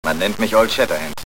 Hörprobe des deutschen Synchronschauspielers (20 Kb)